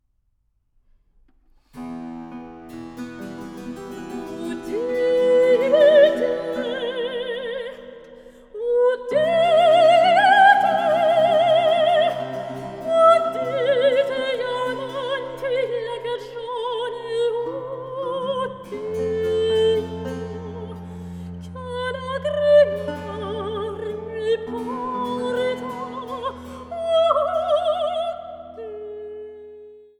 Sopran
Cembalo
Violoncello
Théorbe